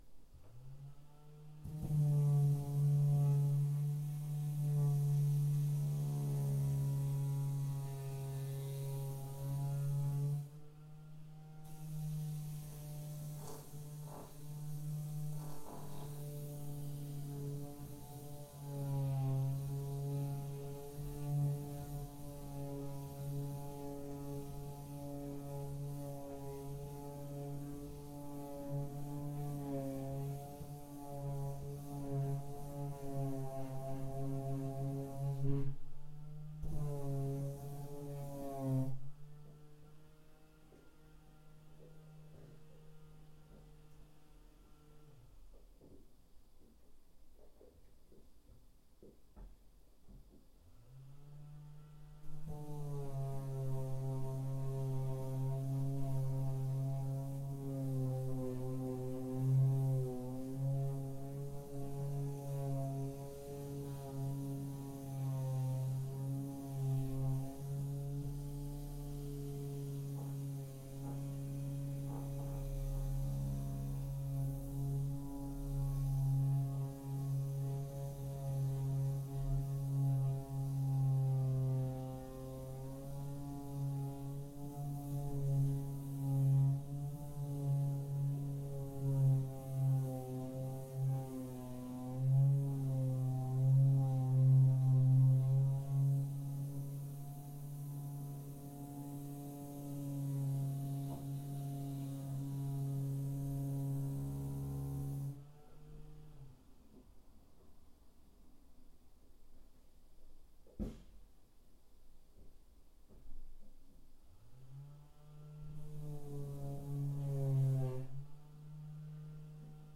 recording meditation
audio recording samples (1st part – “renovations”, 2nd part: “dog”)